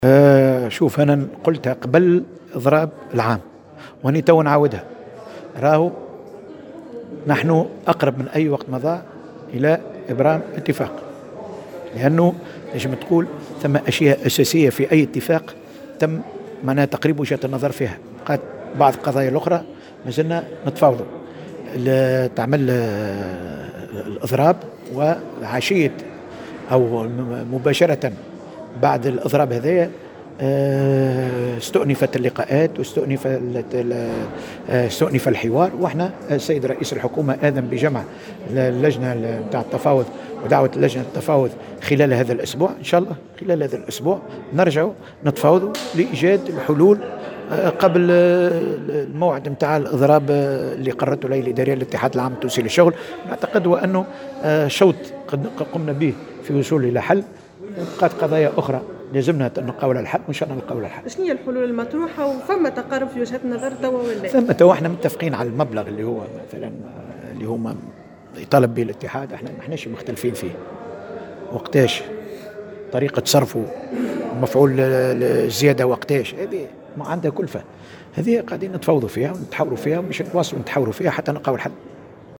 صرّح وزير الشؤون الاجتماعية محمد الطرابلسي اليوم الاثنين على هامش جلسة عامة في البرلمان واكبها مراسل "الجوهرة أف أم" انه بعد قرار الاضراب العام استؤنفت اللقاءات والحوار، مؤكدا حصول اتفاق حول مبلغ الزيادة.